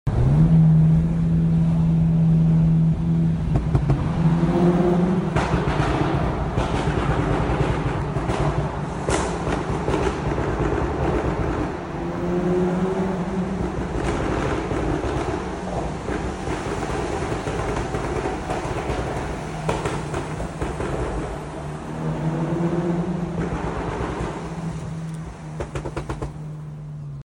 Sound From A SCIROCCO R Sound Effects Free Download